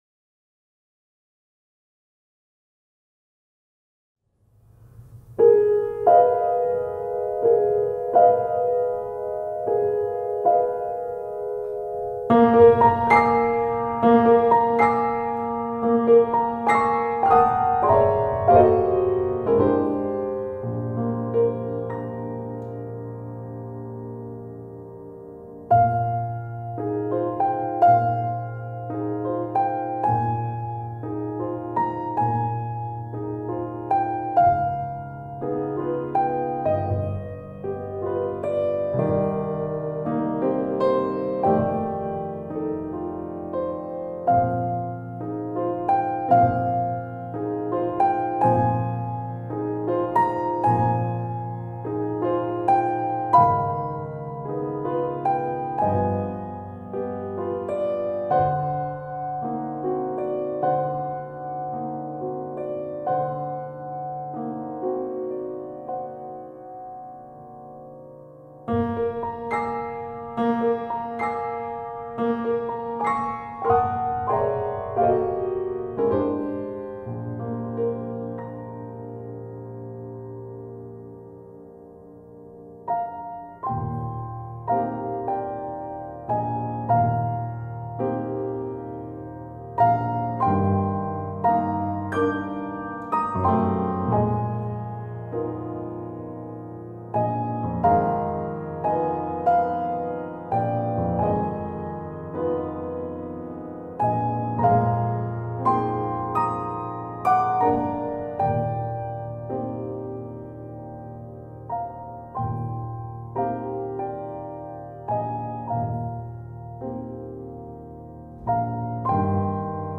1. The opening chords sound like the wavy, blurring lines used in movies to indicate a flashback.
2. Those chords are followed at 0:12 by the sounds of kids splashing and playing.
3. The parallel fourths starting at 1:22 are silly but melodic.
Tags1910s 1915 classical Europe-Asia